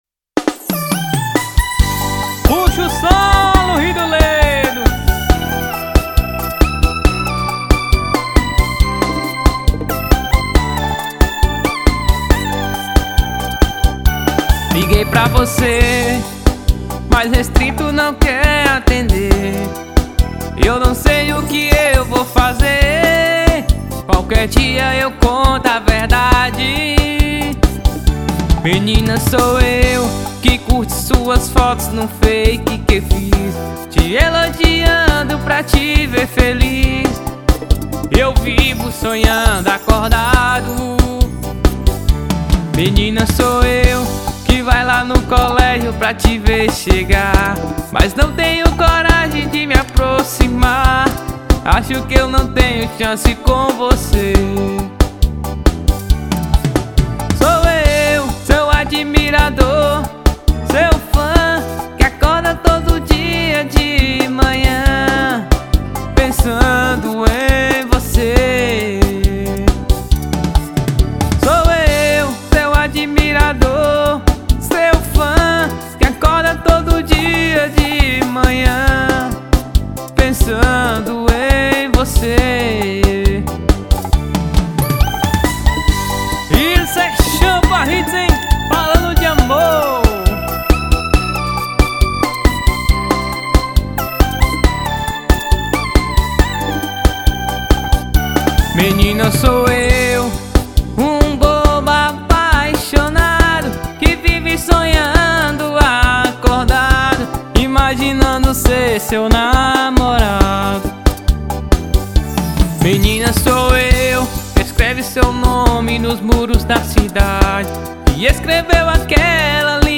EstiloArrochadeira